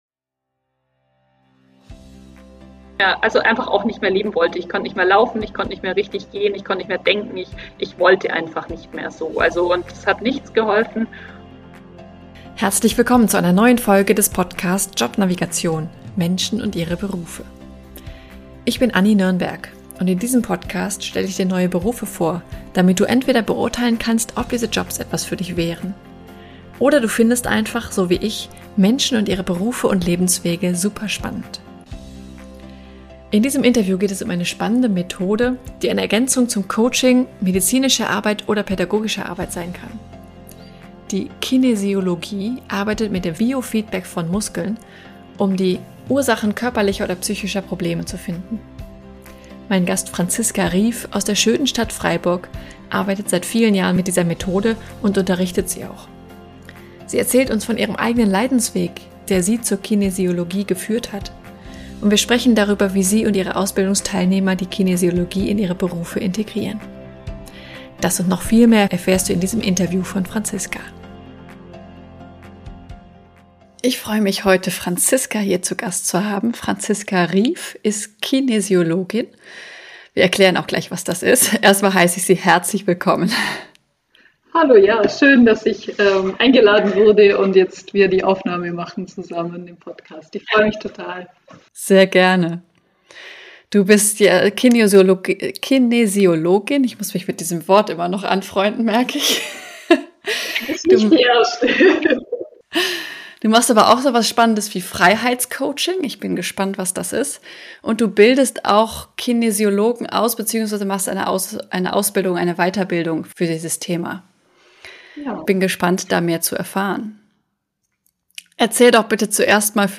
In diesem Interview geht es um eine spannende Methode, die eine Ergänzung zum Coaching, oder medizinischer oder pädagogischer Arbeit sein kann.